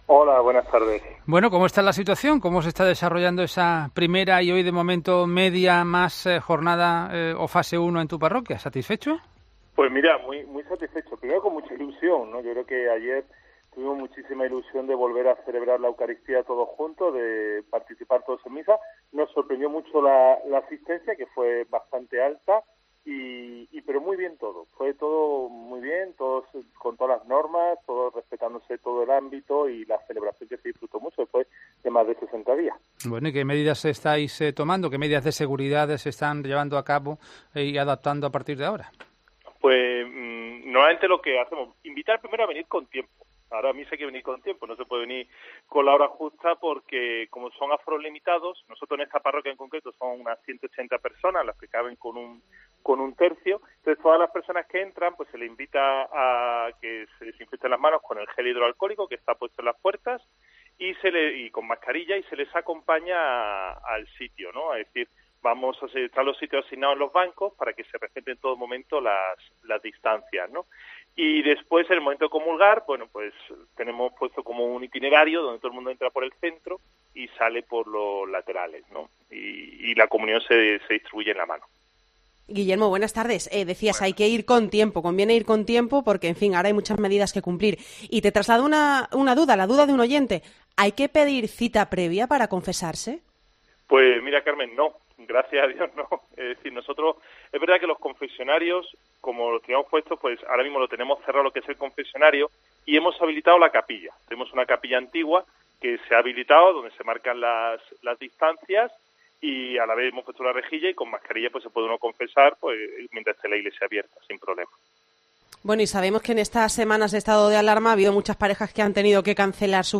asegura durante una entrevista en COPE Málaga